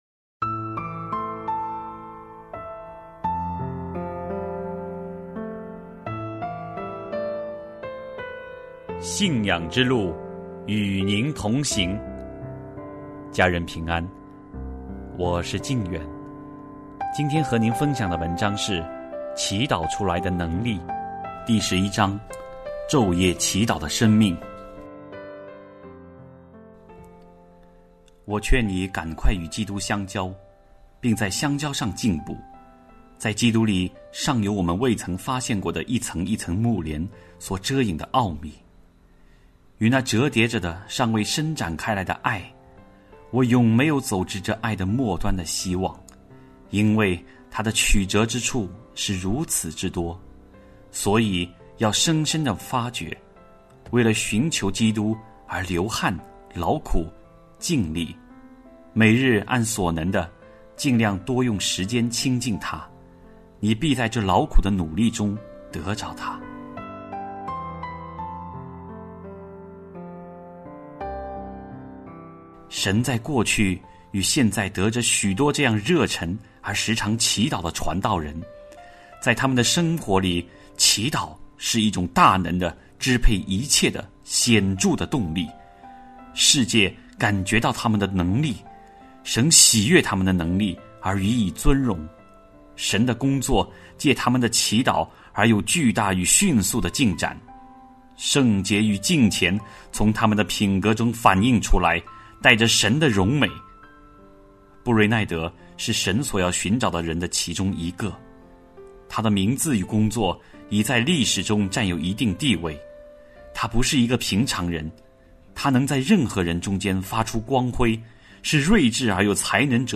首页 > 有声书 | 灵性生活 | 祈祷出来的能力 > 祈祷出来的能力 第十一章：昼夜祈祷的生命